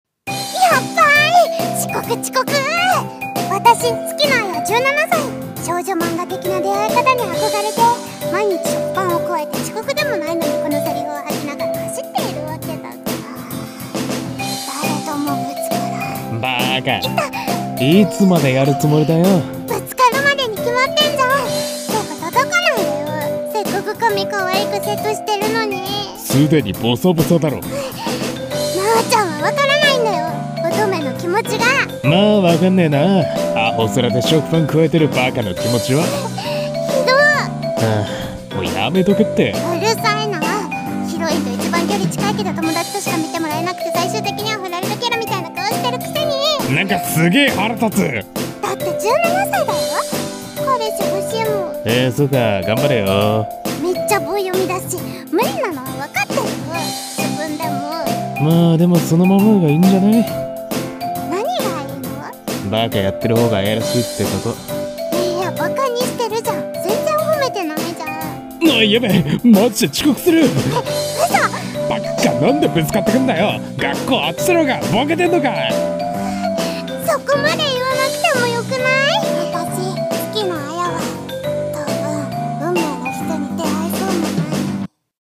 【二人声劇】月野あやは恋がしたい！！